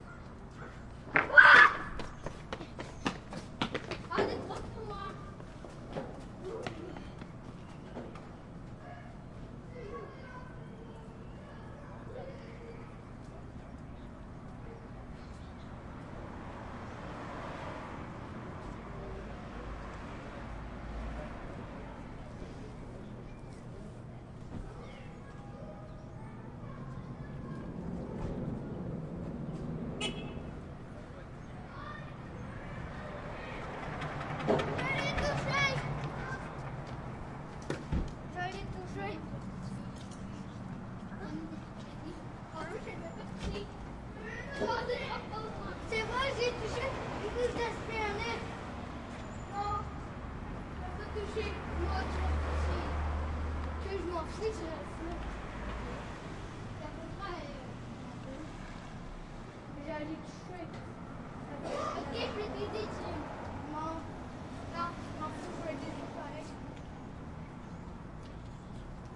蒙特利尔 " 魁北克儿童在阳台上玩喊叫的后院小巷1凡尔登，蒙特利尔，加拿大
描述：孩子魁北克玩从阳台1凡尔登，蒙特利尔，Canada.flac呼喊后院胡同